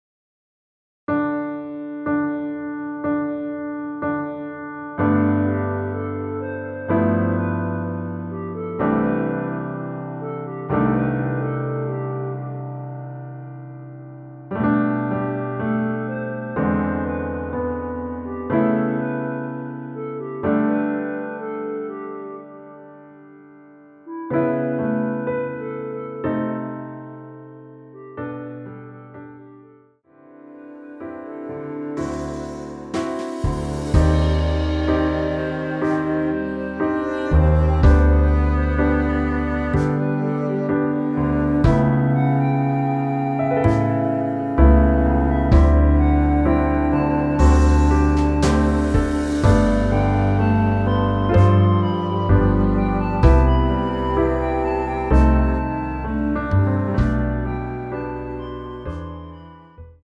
원키 멜로디 포함된 MR입니다.
앞부분30초, 뒷부분30초씩 편집해서 올려 드리고 있습니다.